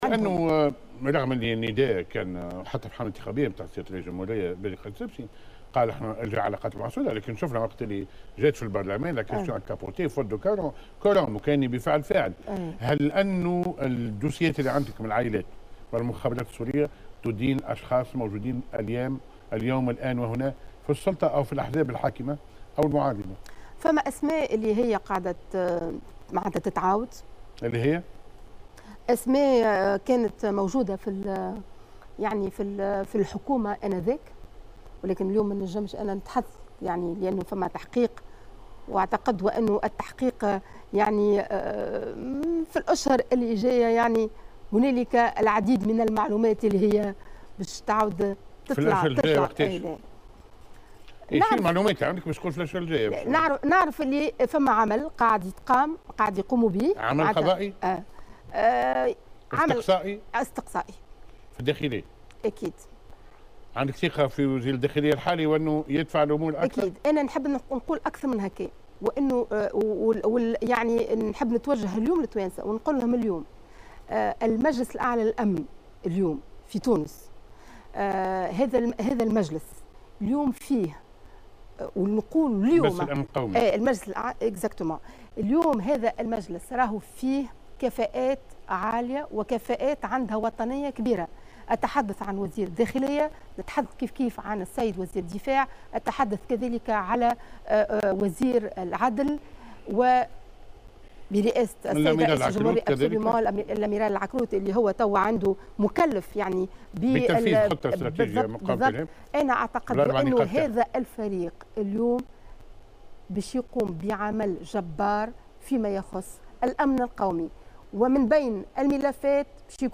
قالت ليلى الشتاوي، عضو لجنة التحقيق في تسفير الشباب إلى مناطق القتال إنه سيتم خلال الأشهر القادمة الكشف عن معطيات جديدة بخصوص ملف تسفير الشباب. وأضافت في حوار لها على القناة الوطنية أن وزارة الداخلية تقوم ببحث استقصائي وتحقيق حول الملف، مشيرة إلى أن هناك عديد المعلومات التي سيتم الكشف عنها، كما أن هناك عديد الأسماء المورطة في هذا الملف والتي تم تداولها في السابق ستطرح من جديد، وفق تعبيرها.